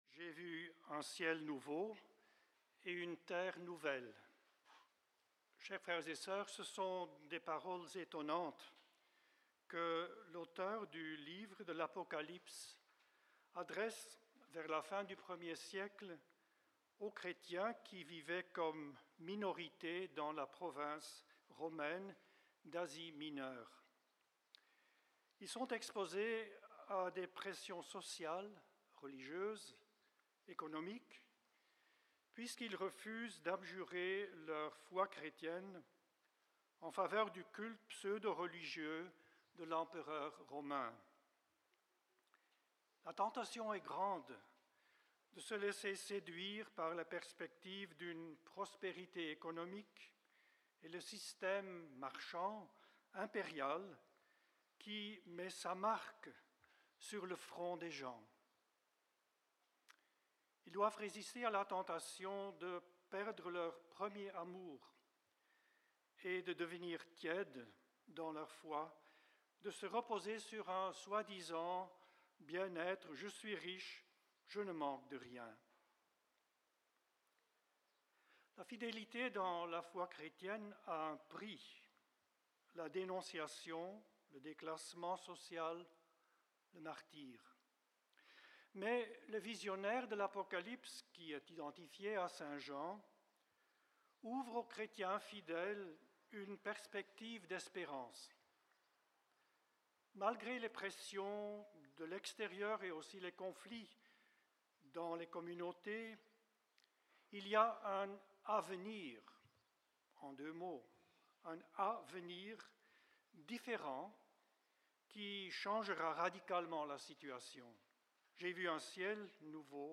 En ce cinquième dimanche de Pâques